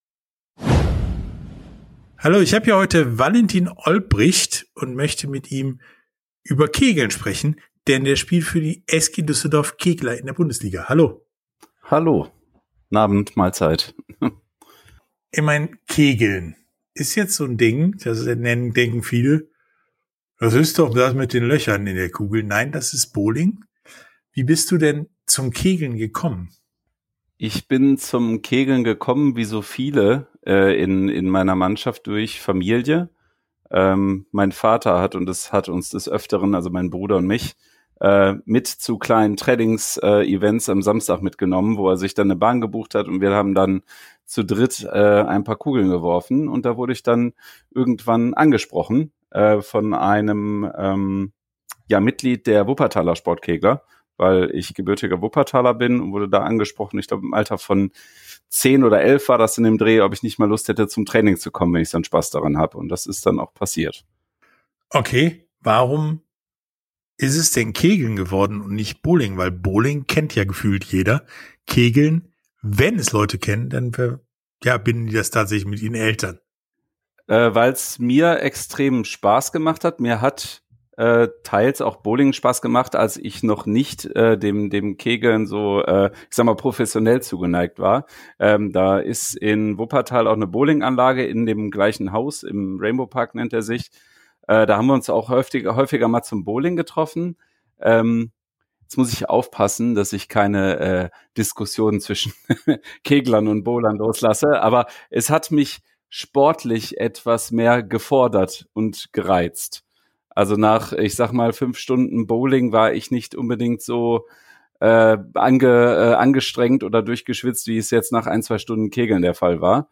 Sportstunde - Interview komplett Kegeln ~ Sportstunde - Interviews in voller Länge Podcast